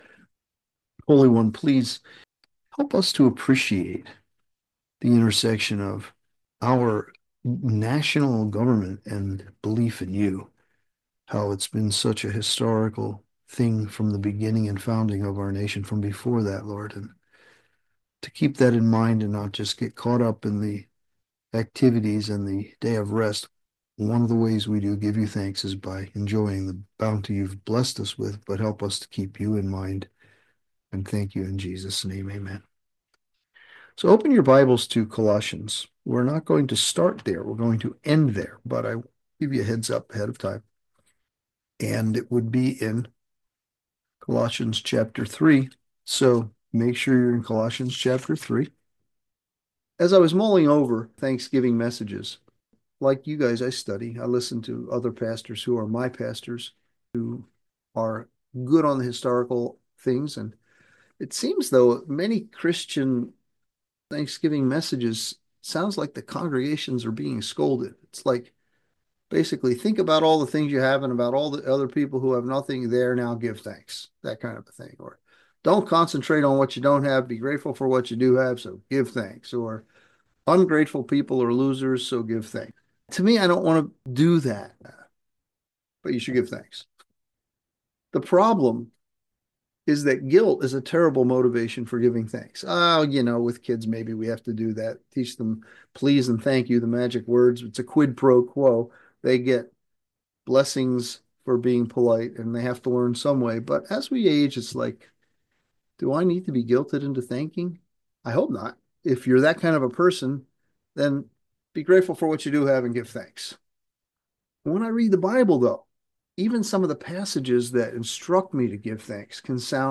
Sermons | Calvary Chapel on the King's Highway